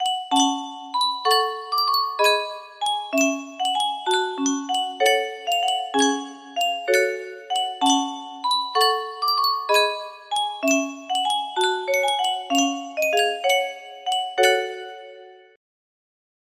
Yunsheng Music Box - Greensleeves 070Y music box melody
Full range 60